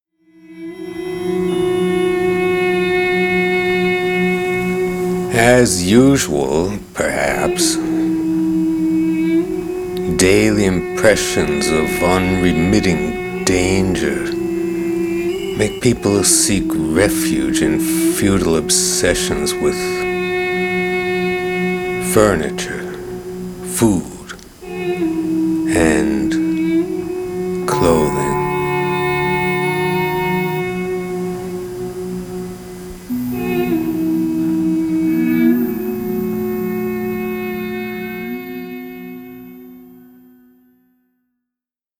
Sound Art Series